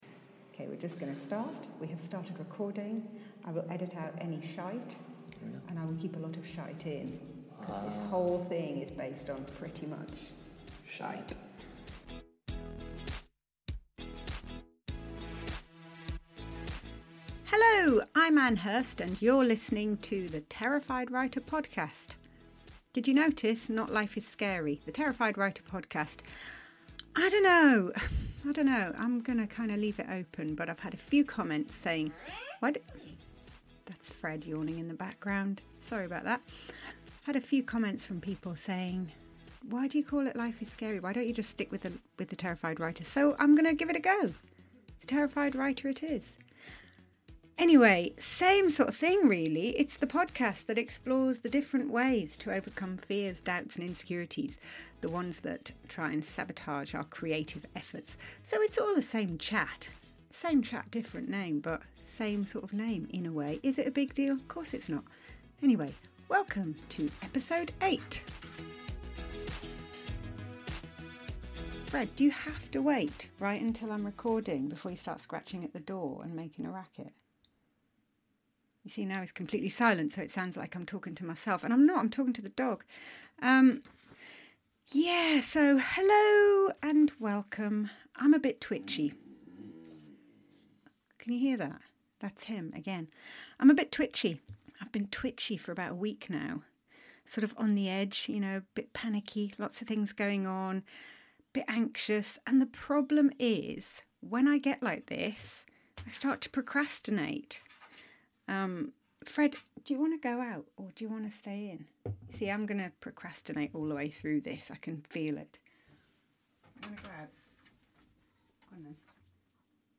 Also, Fred the dog gets involved and you can witness a bit of real time yips, creative u-turns and procrastination.